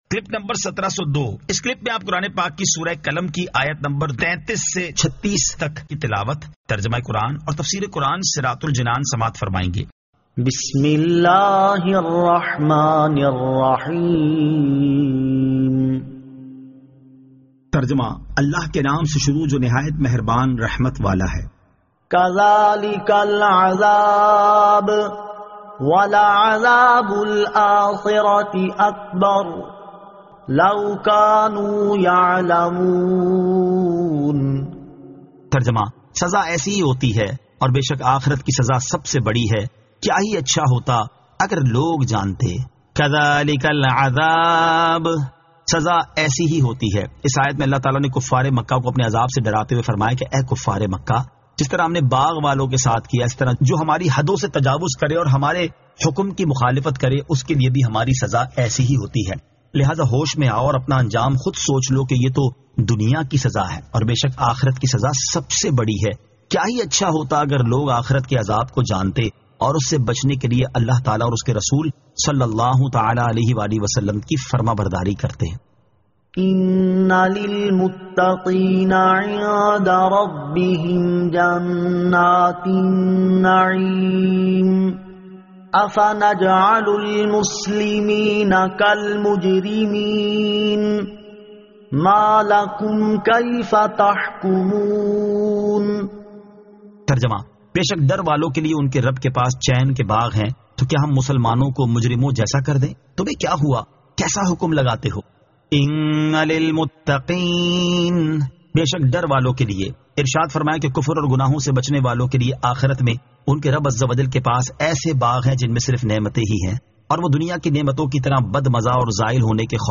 Surah Al-Qalam 33 To 36 Tilawat , Tarjama , Tafseer